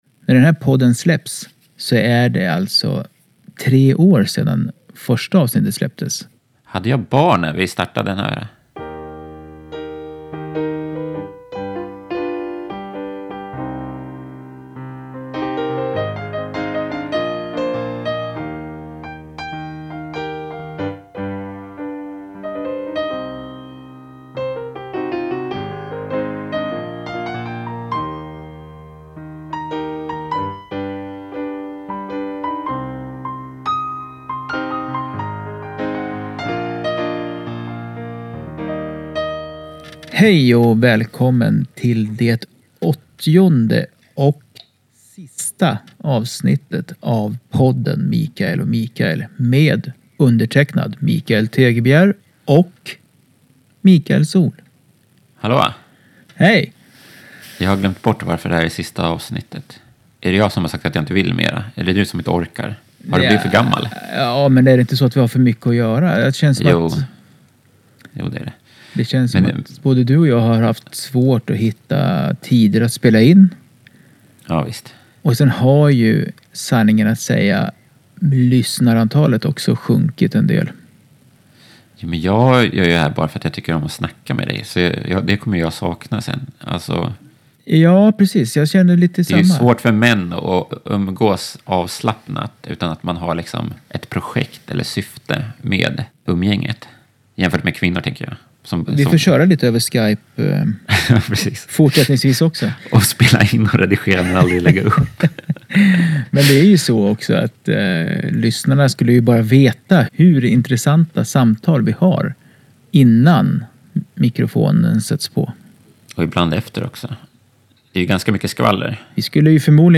De pratar om allt. Det är helt uppåt väggarna.